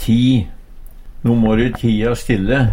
ti - Numedalsmål (en-US)